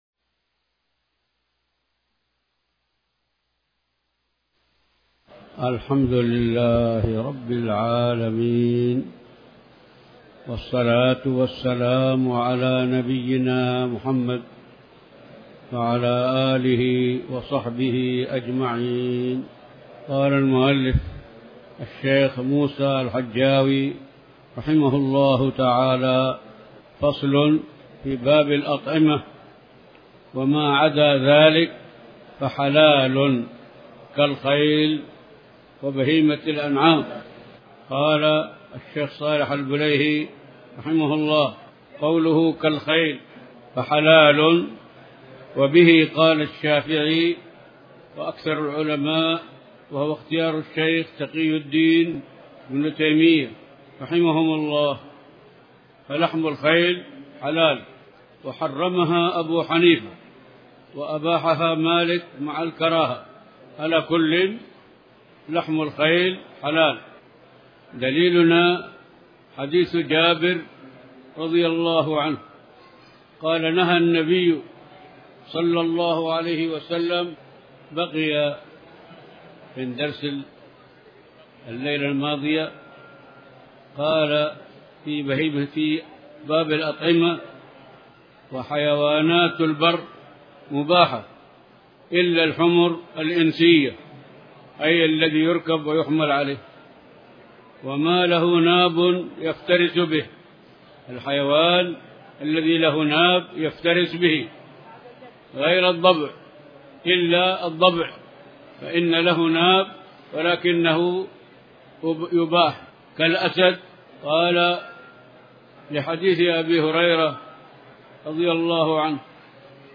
تاريخ النشر ٢٢ محرم ١٤٤٠ هـ المكان: المسجد الحرام الشيخ